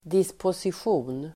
Uttal: [disposisj'o:n]